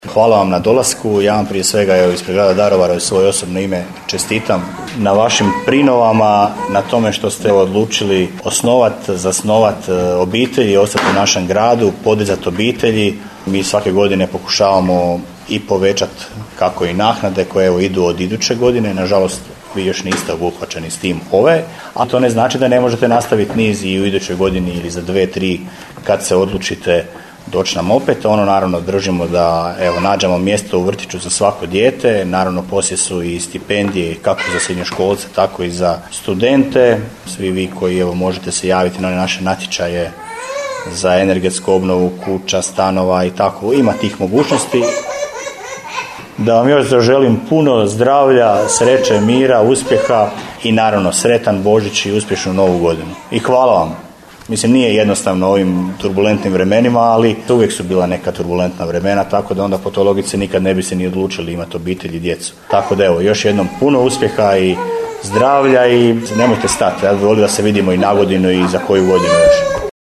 Za djecu Daruvara od Grada sa srcem darovi i prigodno obraćanje bebama, njihovim mamama i tatama gradonačelnika Daruvara Damira Lneničeka